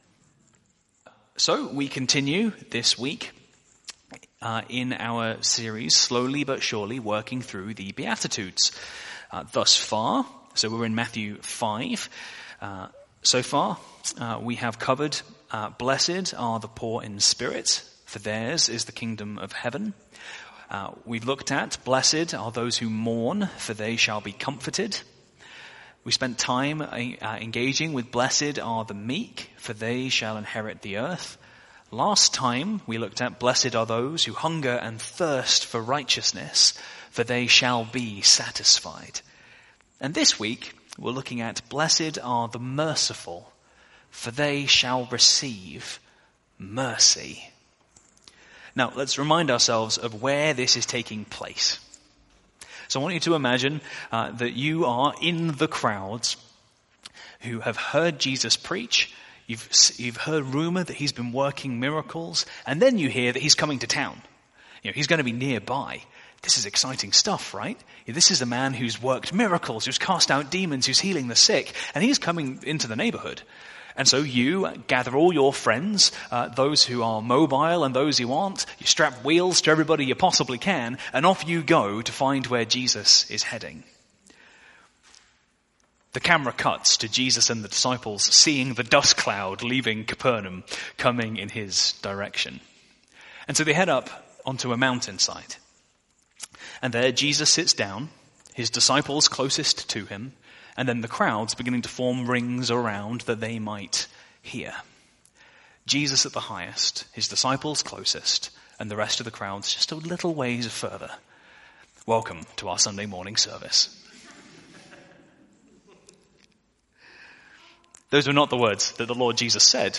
Sermon Series: Beatitudes